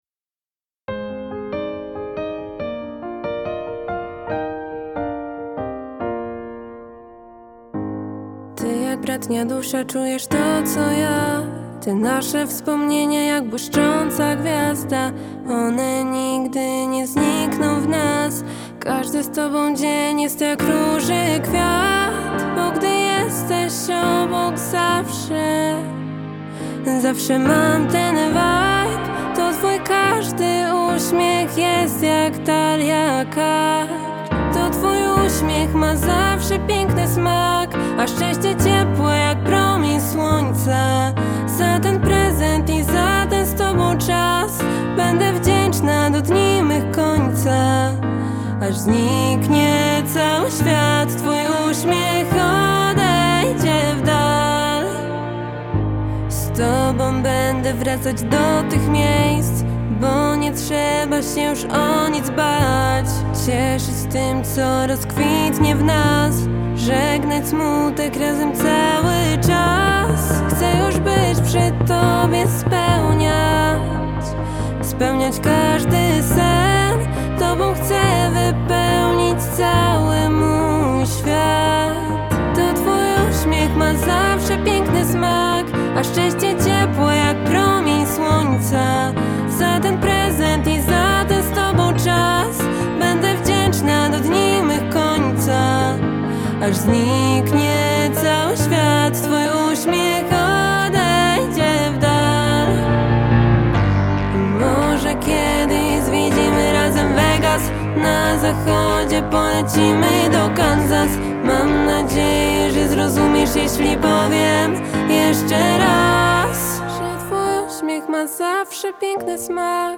Singiel (Radio)
w emocjonalnej, przepięknej balladzie!